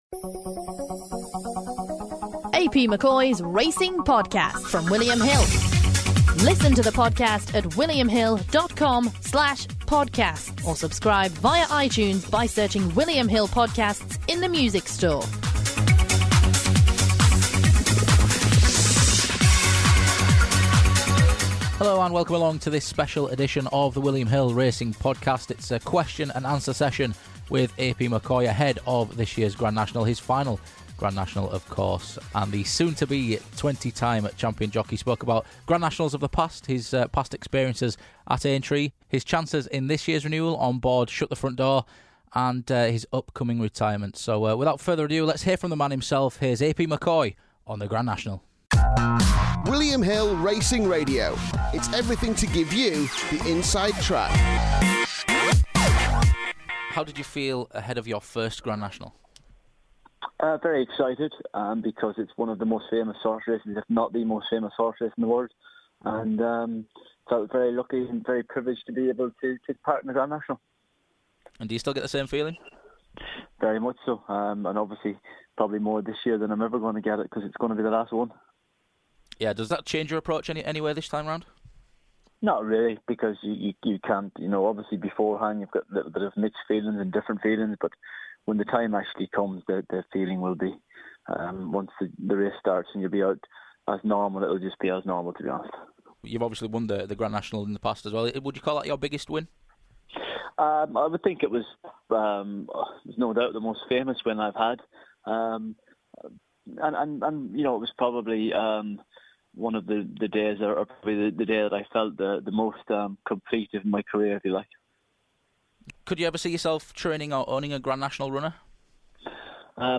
AP McCoy - Grand National Q&A